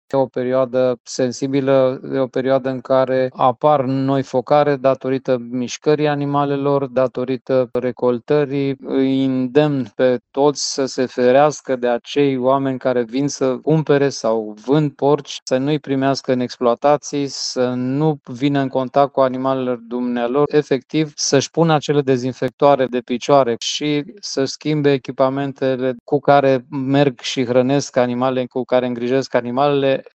Directorul DSVSA a subliniat că în doar câteva ore după confirmarea focarelor s-a aprobat planul de măsuri, animalele infectate fiind ucise şi duse la incinerare.